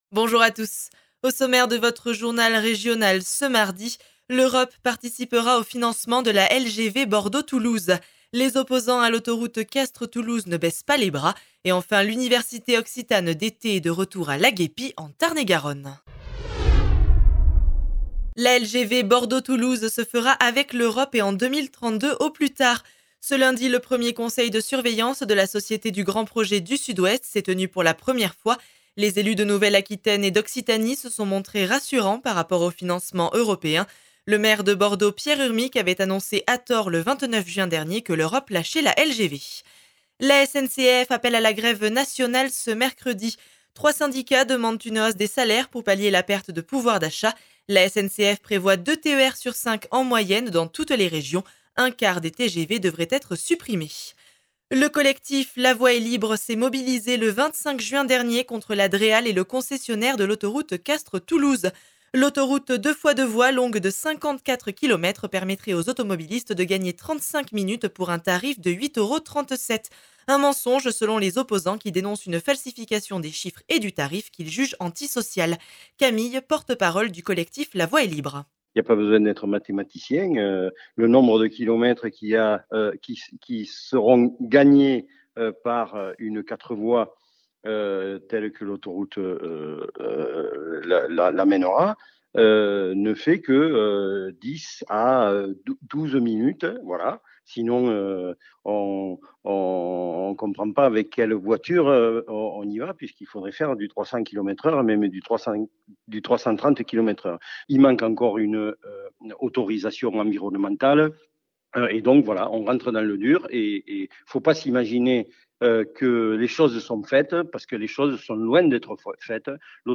journal du 5 Juillet 2022